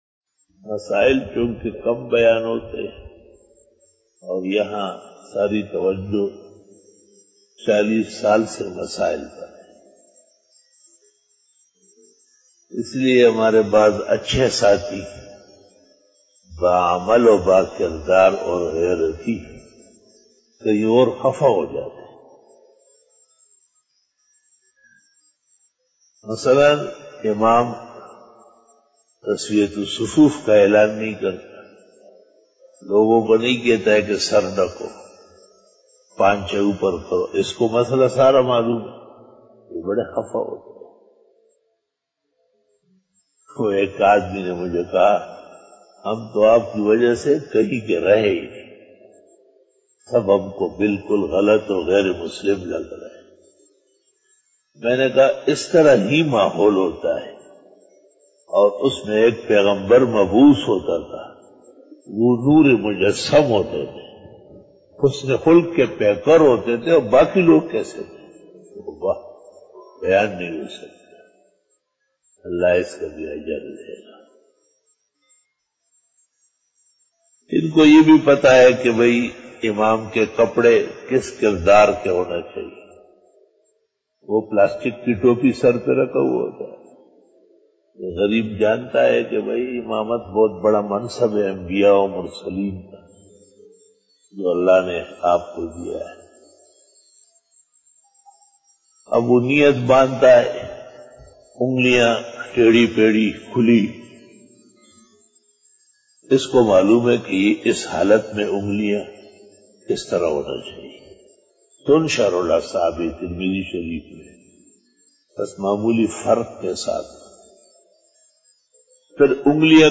بیان بعد نماز فجر